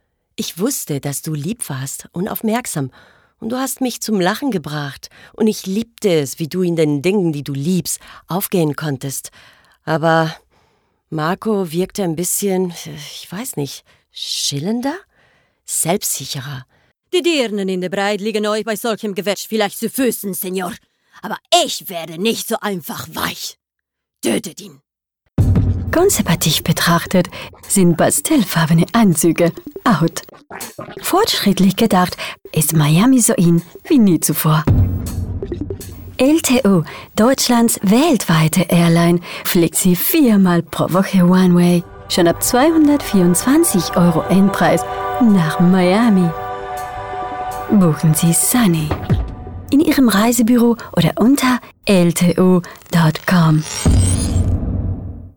Native speaker Female 30-50 lat
Her voice is clear and pleasant.
Nagranie lektorskie w języku niemieckim